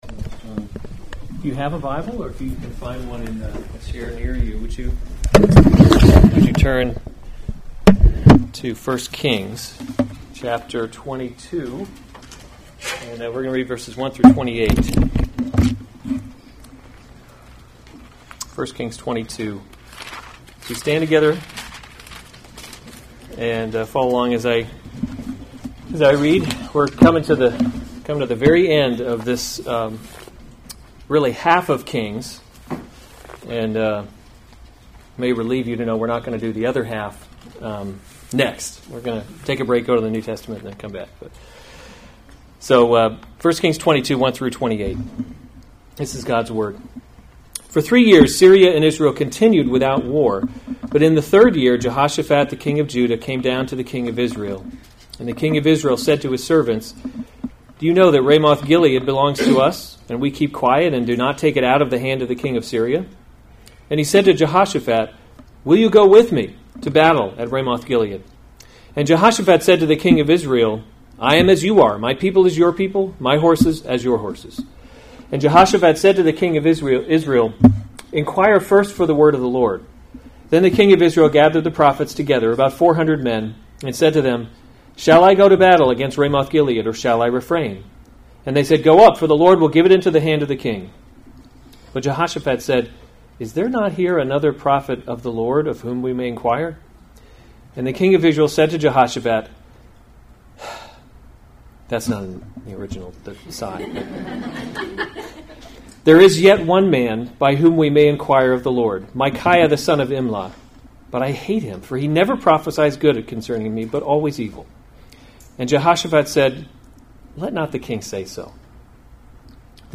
July 20, 2019 1 Kings – Leadership in a Broken World series Weekly Sunday Service Save/Download this sermon 1 Kings 22:1-28 Other sermons from 1 Kings Ahab and the False […]